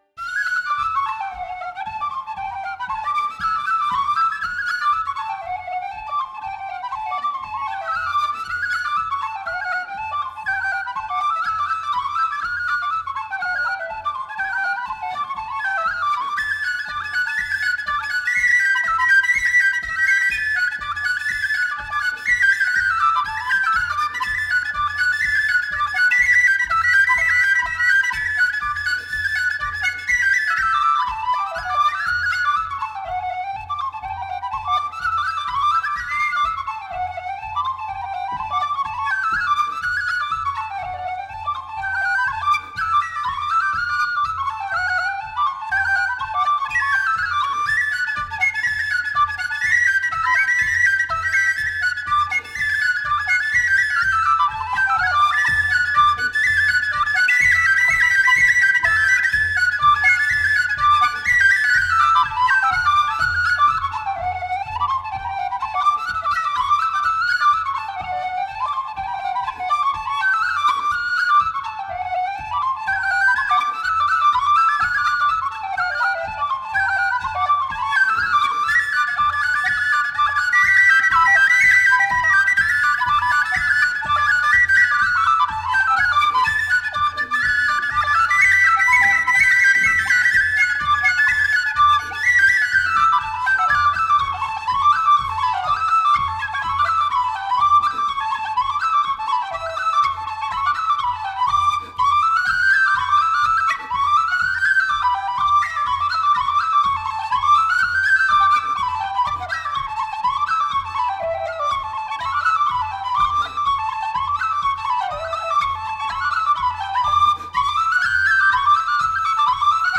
风笛、小提琴等乐器逐渐发展出爱尔兰音乐的固有特色。
幸好，这是一张现场录音的演奏会唱片。